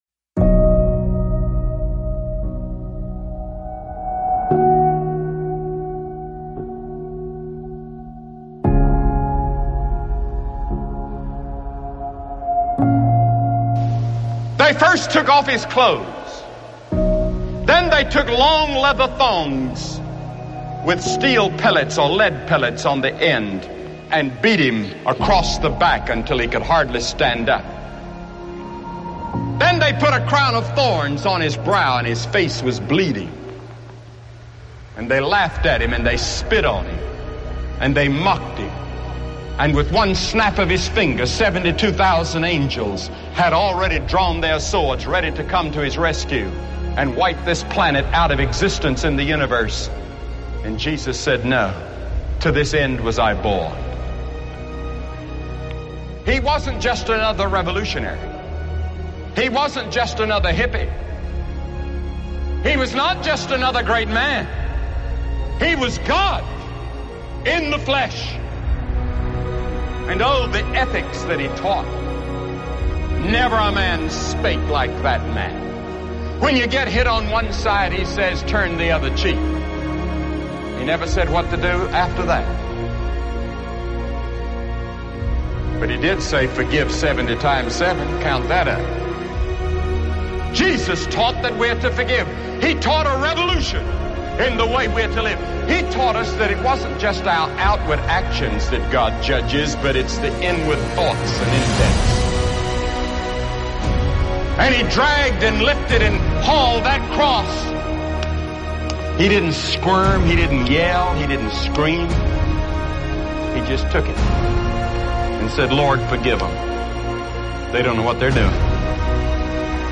“Forgive.” Spoken word sermon excerpt by Billy Graham.